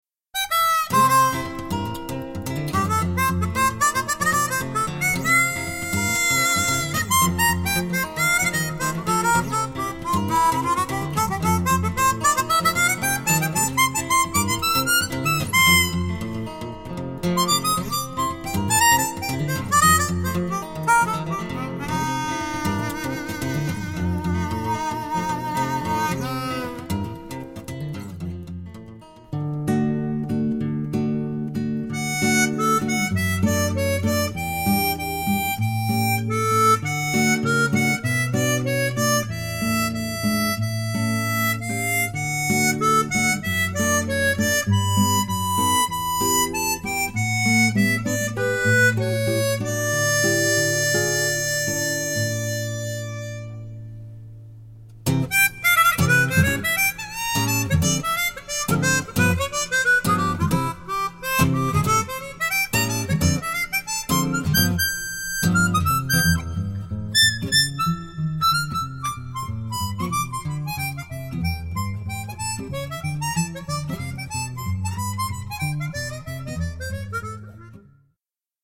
透き通った音色を持っていますが、吹き方によってその音色は大きく変化し、奏者の個性がとても良く出るハーモニカと言う事ができるでしょう。